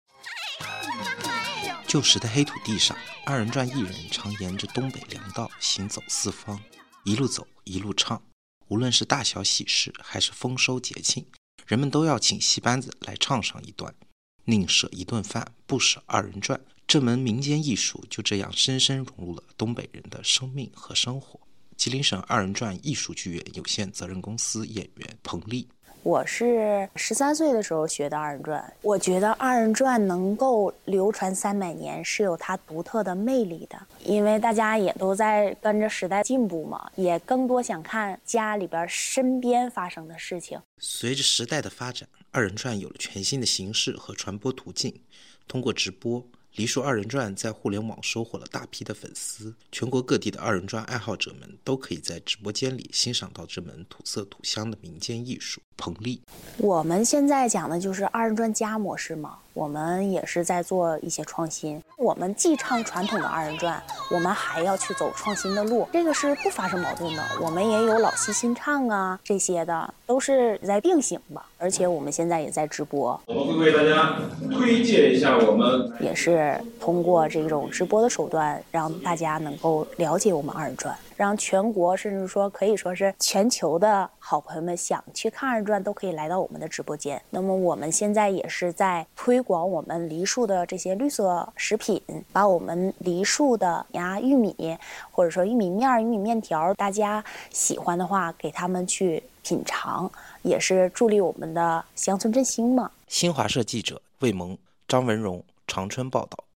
吉林二人转艺术剧院表演。
吉林二人转艺术剧院直播。